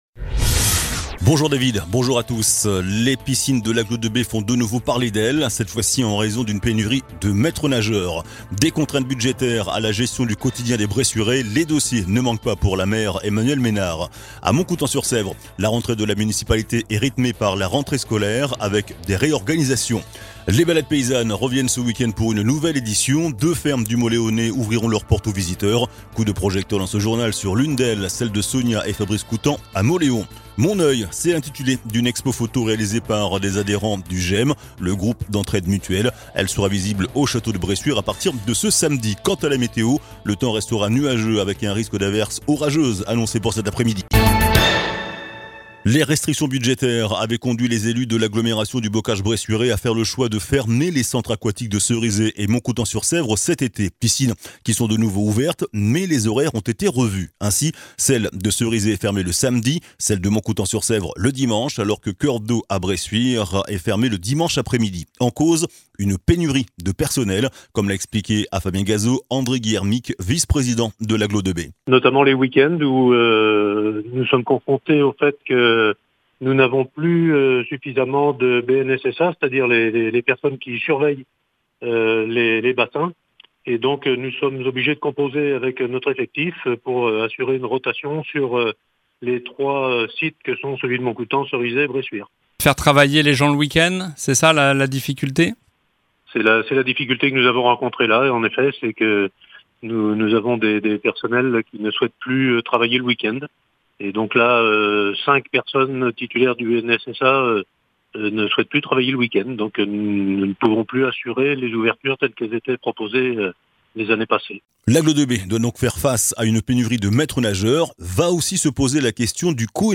JOURNAL DU MERCREDI 07 SEPTEMBRE ( MIDI )